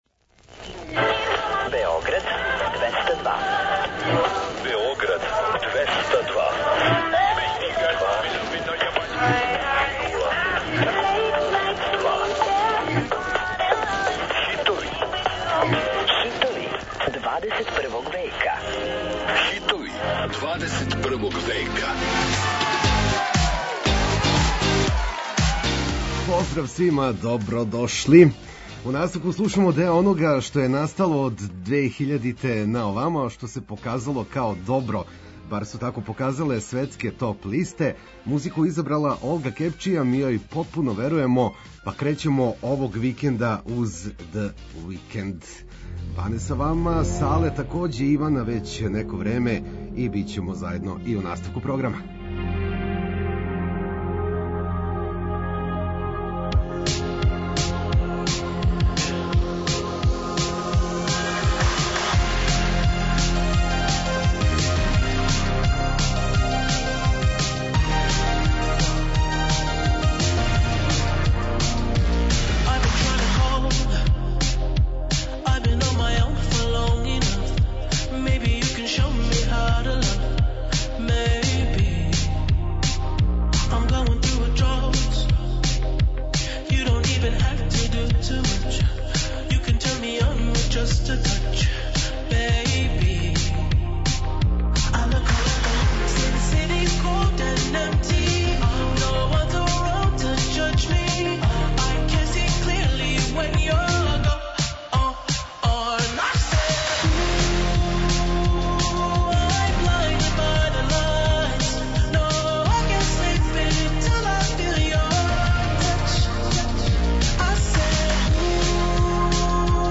На таласе Двестадвојке стижу нумере које освајају радио станице широм планете.